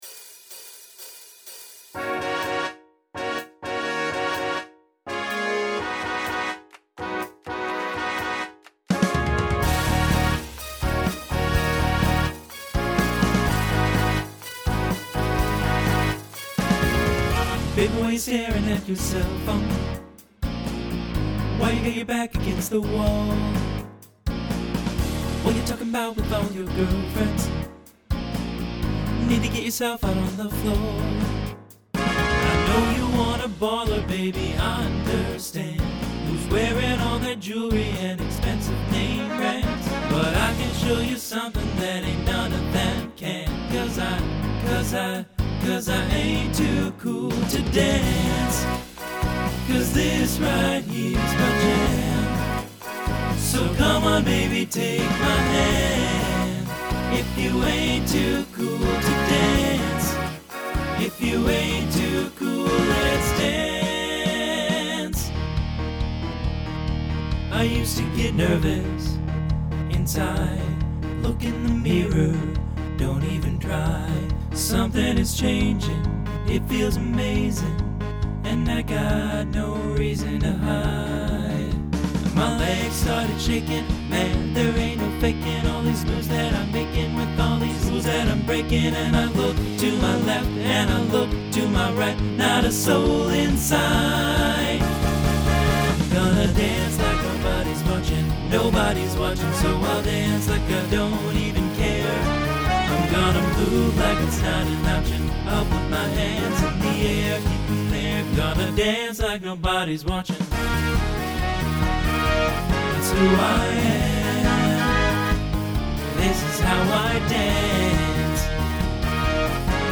Genre Pop/Dance , Rock
Voicing TTB